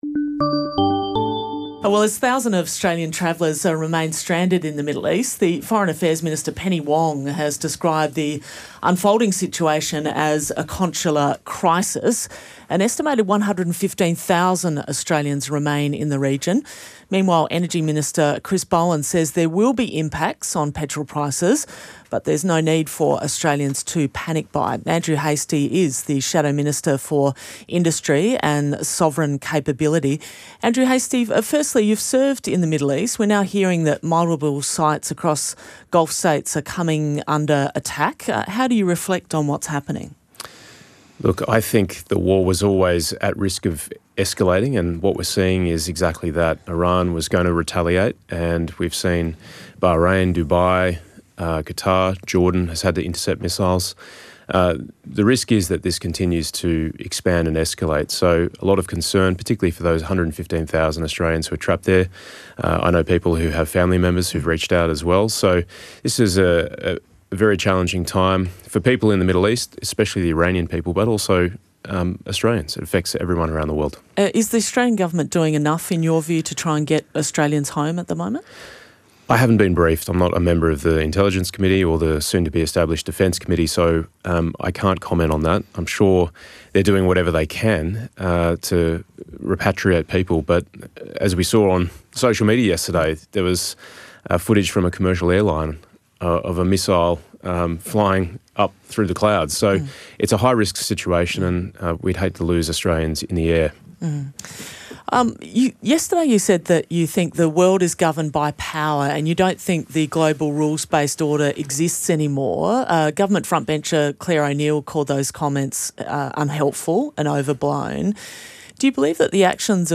• GUEST: Andrew Hastie, Shadow Minister for Industry and Sovereign Capability